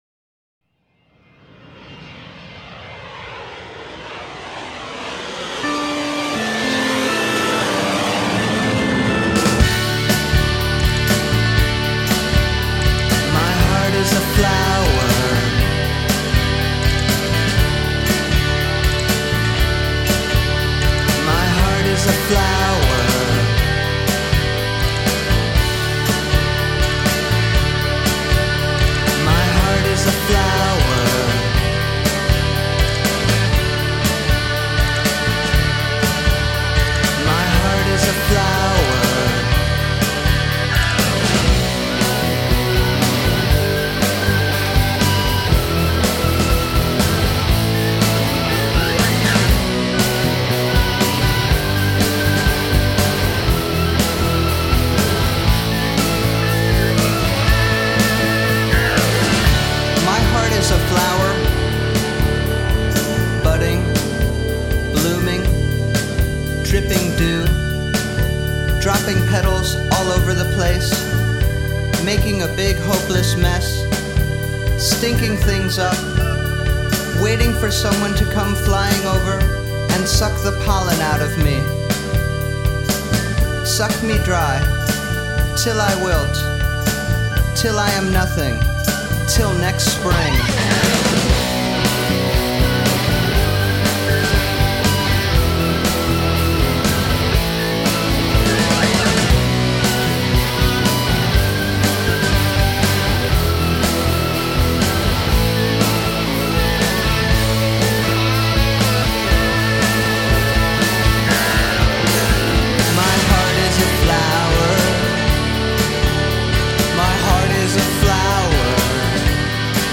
rock poetry